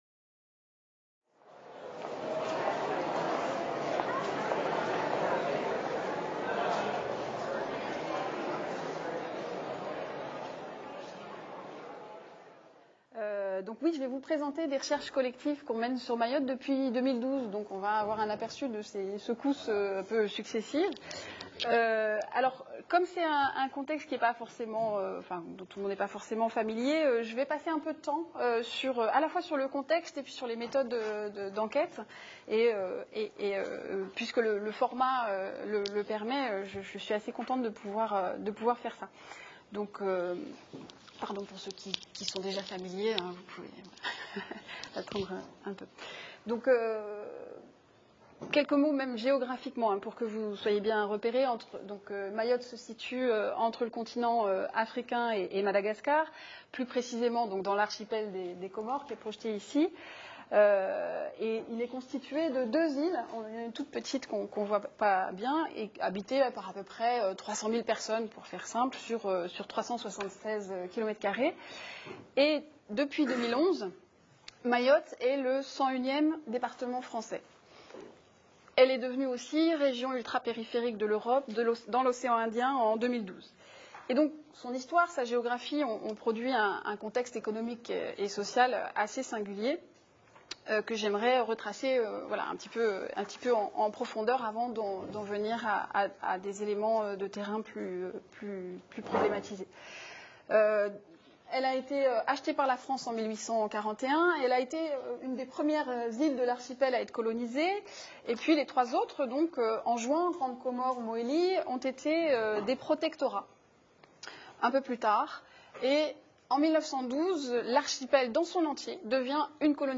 Intervention
lors du séminaire Migrations et altérité (Urmis Nice) le vendredi 9 novembre 2018.